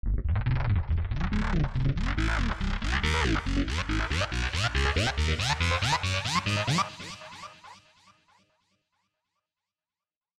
Dj Mix Transition Sound Effect Free Download
Dj Mix Transition